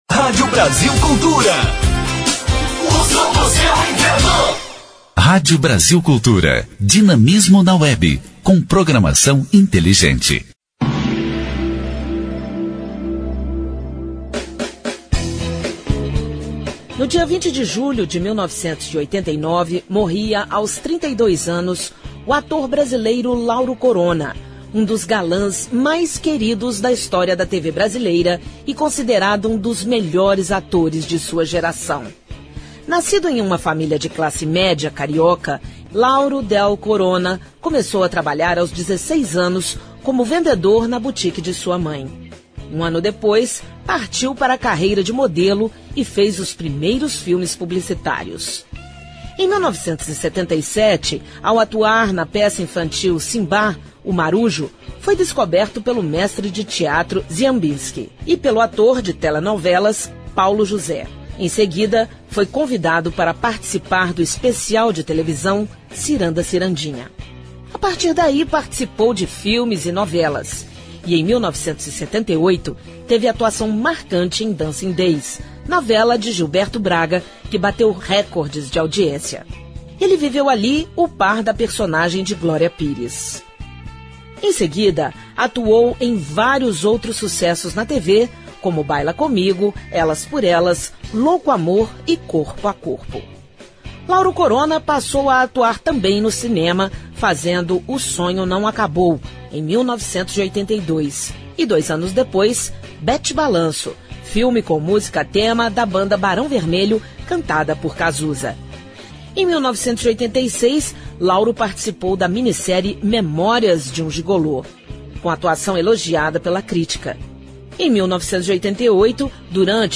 História Hoje : Programete sobre fatos históricos relacionados às datas do calendário.